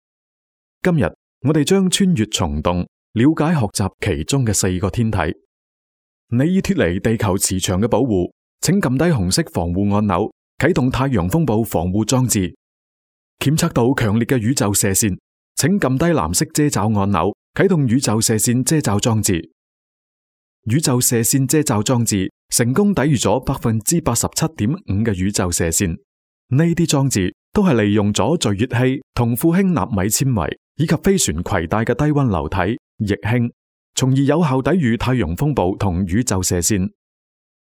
职业配音员全职配音员
• 男粤37 宣传片 梅西爾天體-粤语广式港式 沉稳|娓娓道来|积极向上